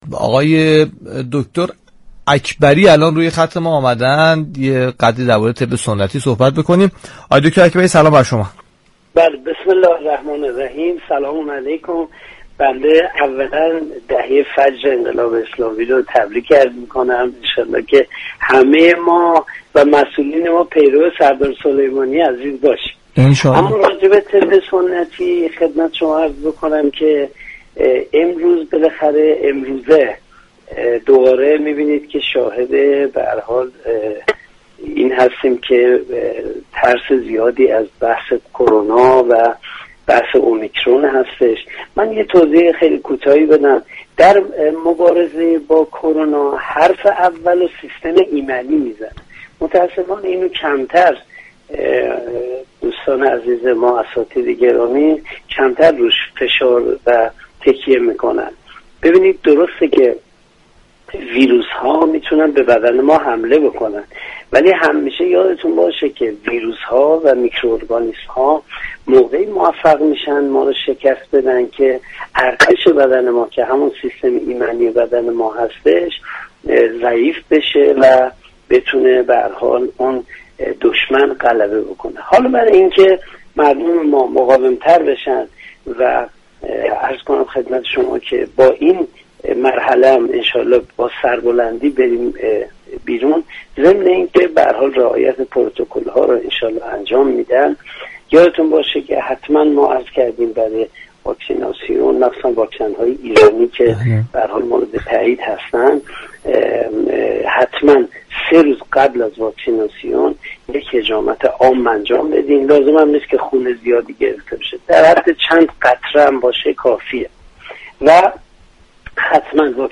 در گفتگو با برنامه تسنیم رادیو قرآن